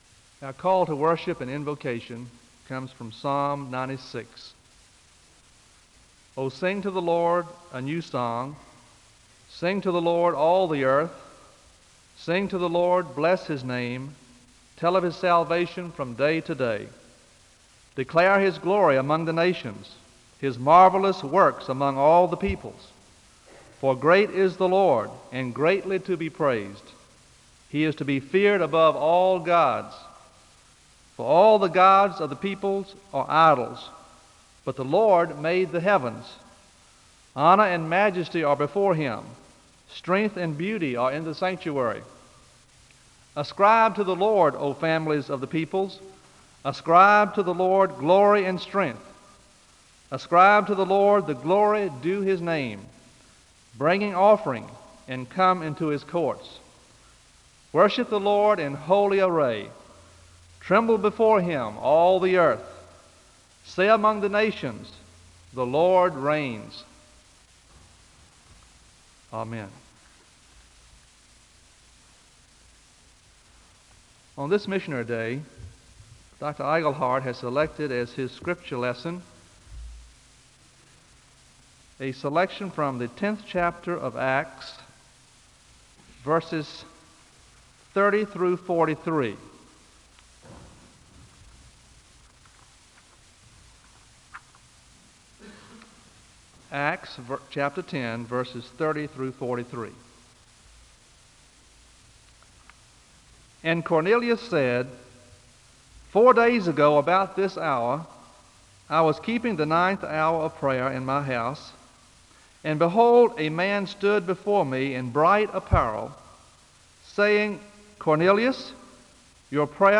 The service begins with a reading from Psalm 96 (00:00-01:10). The speaker reads from Acts 10:30-43 (01:11-04:07). The speaker gives a brief word on the importance of Missionary Day
SEBTS Chapel and Special Event Recordings